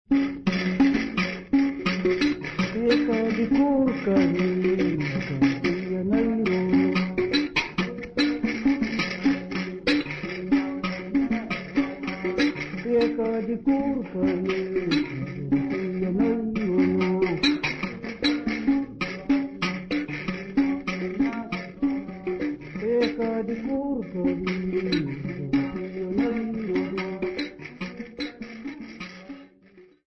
Sambiu church music workshop participants
Sacred music Namibia
Mbira music Namibia
Africa Namibia Sambiu mission, Okavango sx
field recordings
Church song accompanied by the mbira type instrument sisanti and indingo played at both lower and upper key.